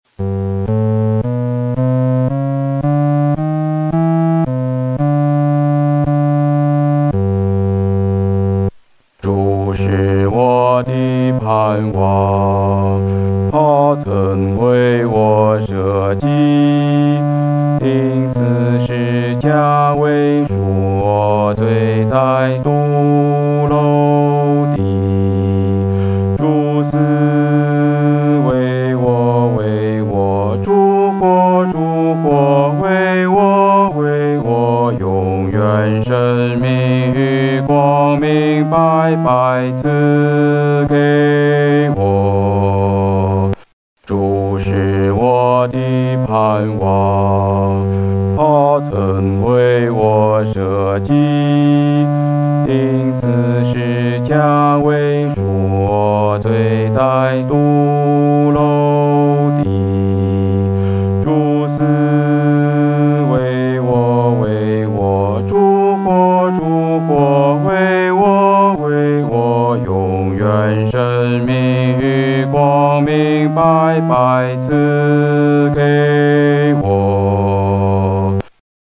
合唱
四声 下载